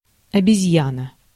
Ääntäminen
IPA: /ˈɑːˌpa/